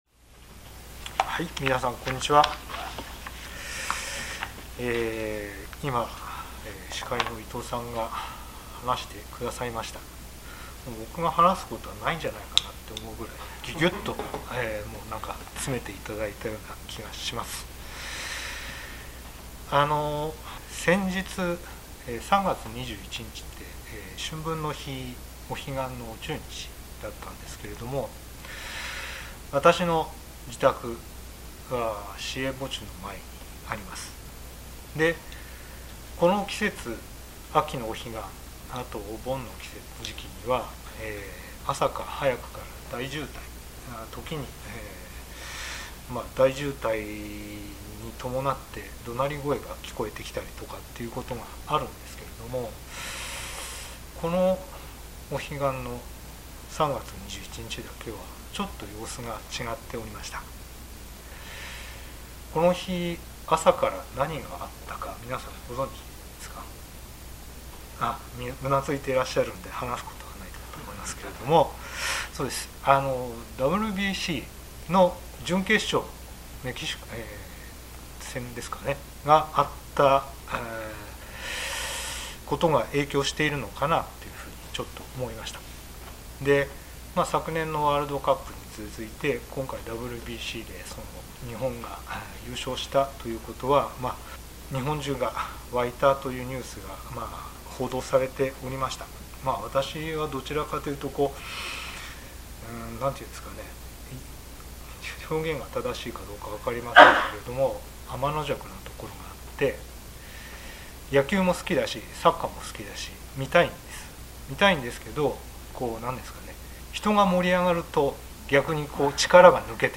聖書メッセージ No.157